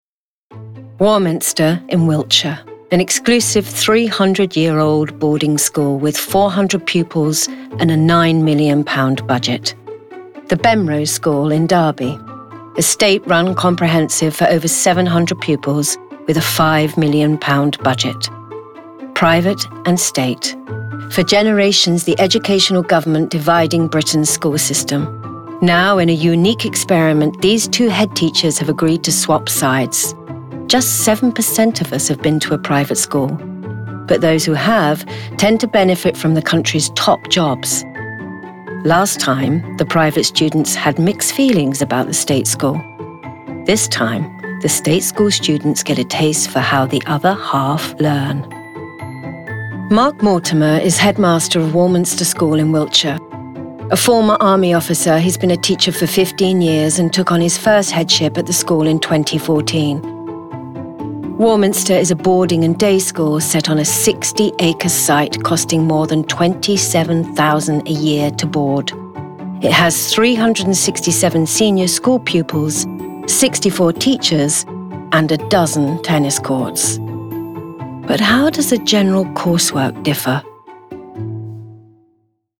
Voice Reel
Narration